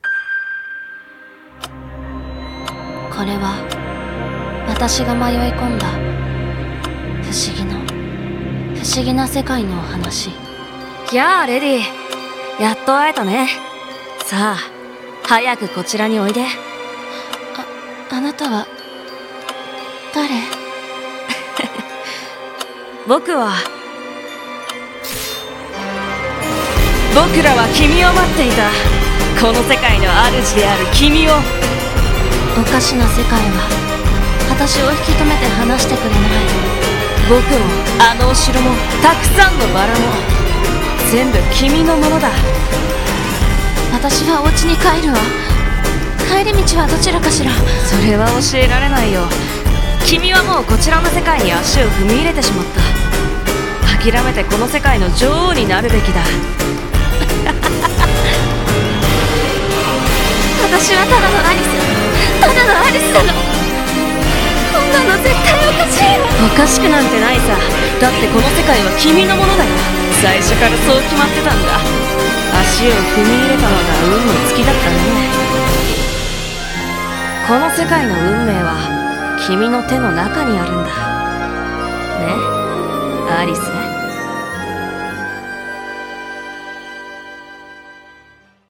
CM風声劇「私のワンダーランド」